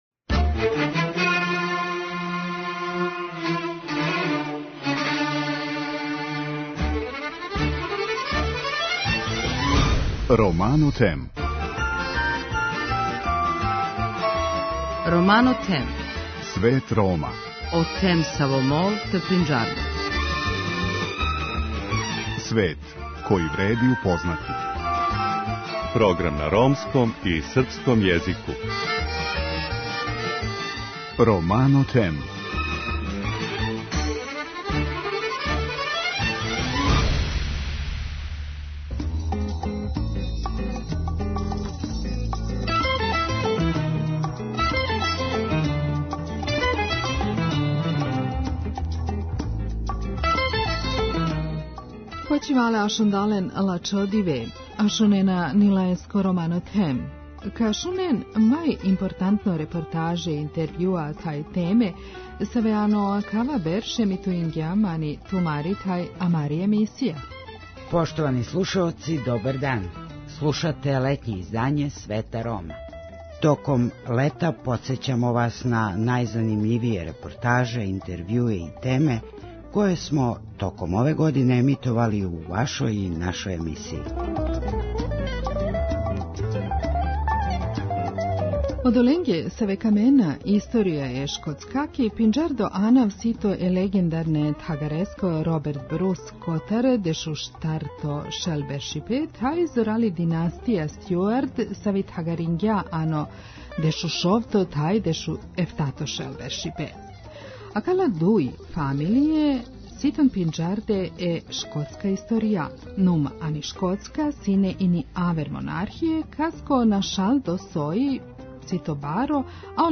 За крај смо оставили занимљиву репортажу о Ромима у Португалији.
преузми : 7.15 MB Romano Them Autor: Ромска редакција Емисија свакодневно доноси најважније вести из земље и света на ромском и српском језику.